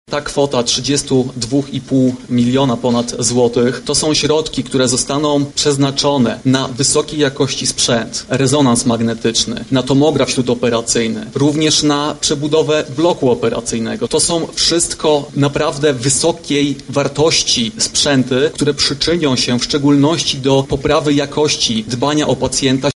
-mówi Wiceminister Zdrowia Marcin Martyniak.